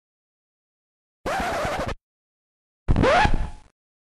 scratch sounddd Download
DISC-SCRATCH-SOUNDS.mp3